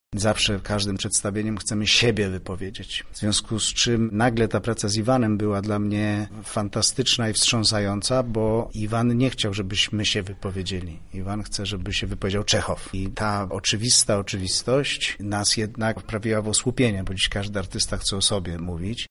Na codzień zajmuję się całkiem innego rodzaju teatrem– mówi Maciej Stuhr, odtwórca roli Michaiła Astrowa.